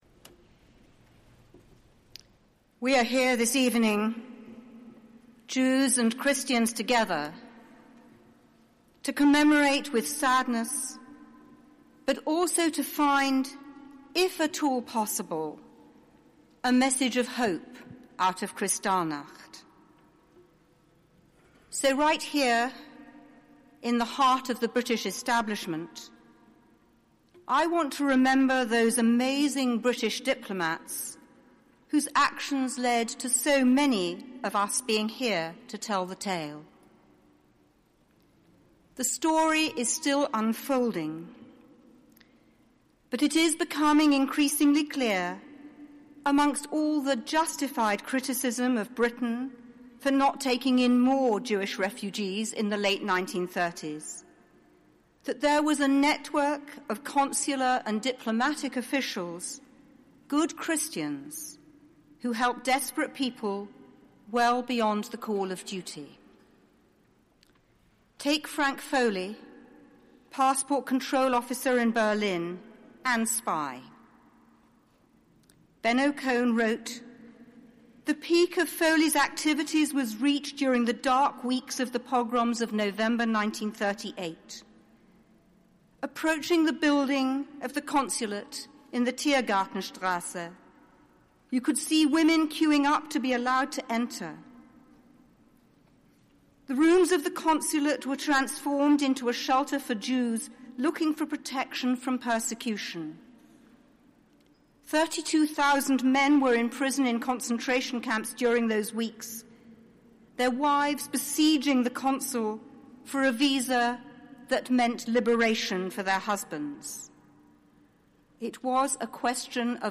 Address given at a Service of solemn remembrance and hope on the 75th anniversary of Kristallnacht
Rabbi The Baroness Neuberger DBE, Senior Rabbi, West London Synagogue